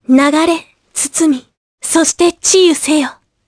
Laias-Vox_Skill2_jp.wav